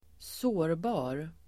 Uttal: [²s'å:rba:r]